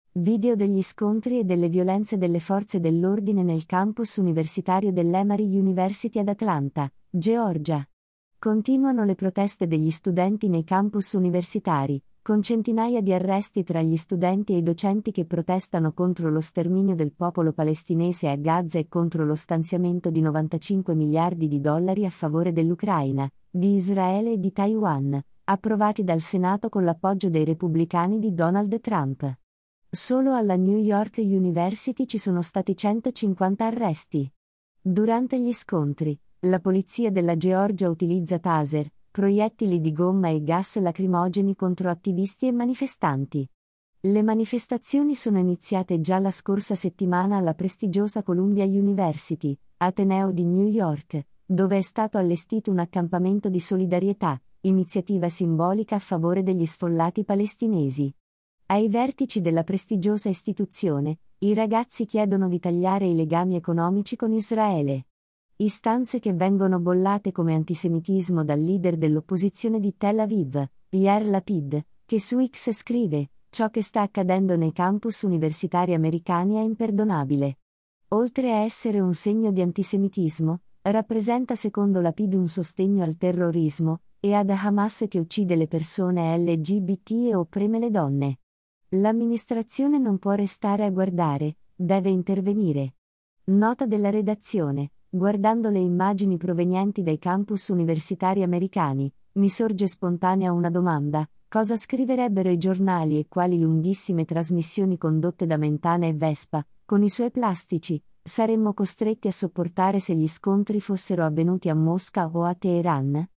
Video degli scontri e delle violenze delle forze dell’ordine nel campus universitario dell’ EMERY UNIVERSITY ad Atlanta (GEORGIA).
Durante gli scontri, la polizia della Georgia utilizza taser, proiettili di gomma e gas lacrimogeni contro attivisti e manifestanti.